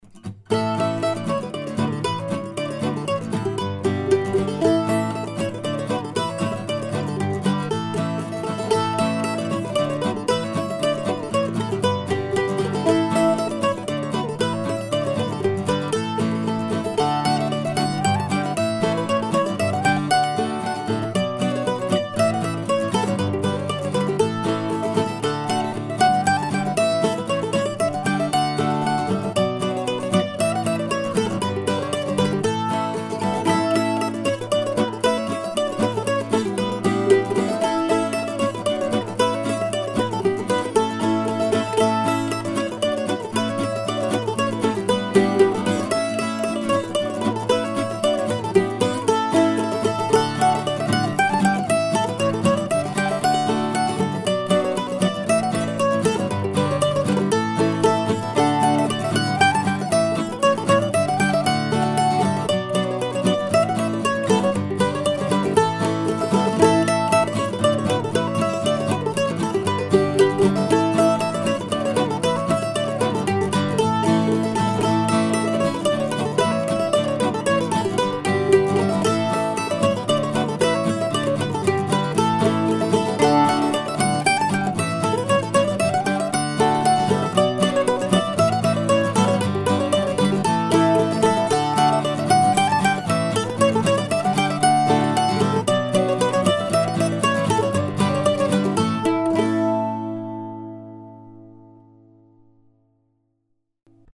Recorded this morning on the now trusty Zoom H4.